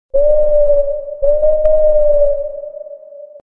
Категория: Живые звуки, имитация